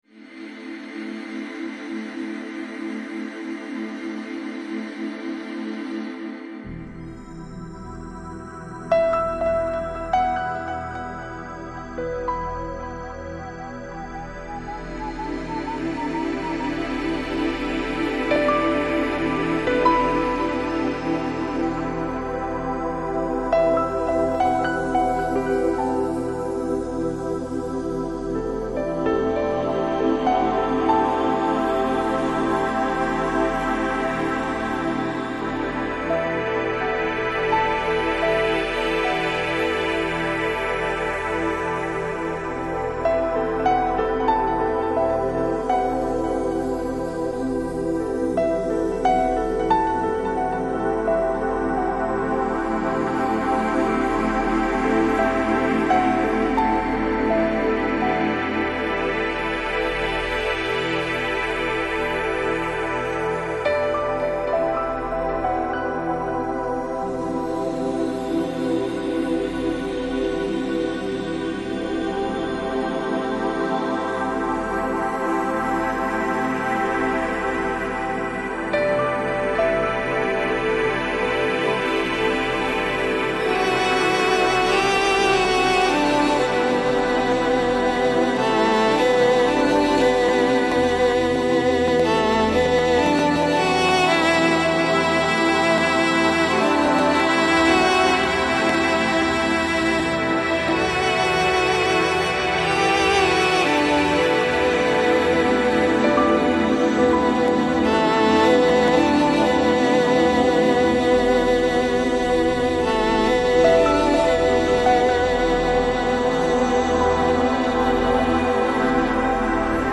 Lounge, Chill Out, Downtempo, Relax Носитель